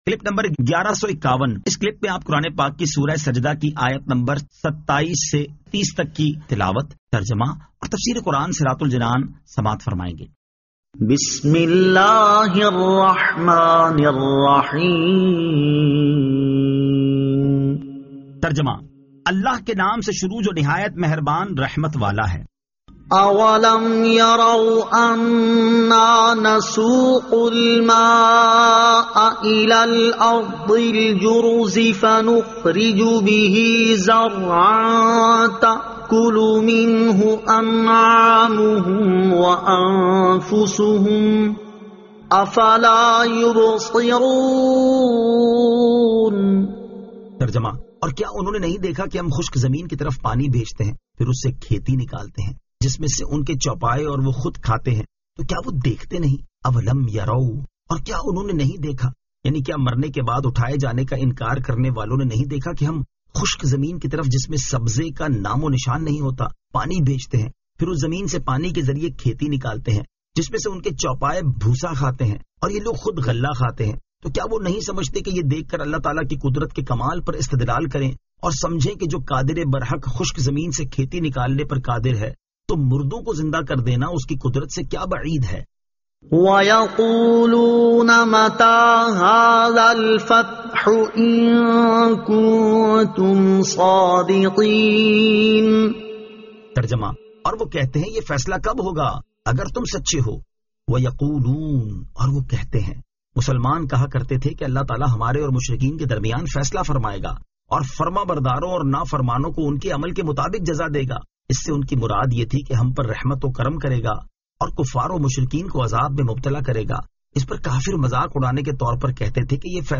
Surah As-Sajda 27 To 30 Tilawat , Tarjama , Tafseer